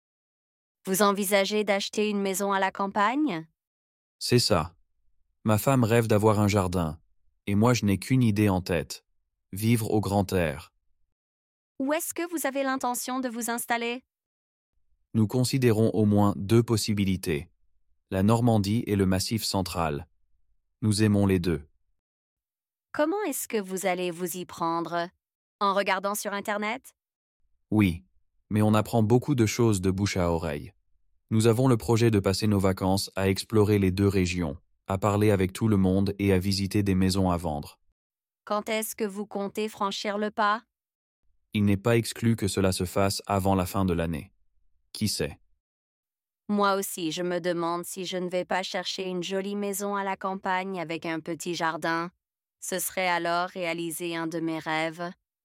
Dialogue B1 : Acheter une maison à la campagne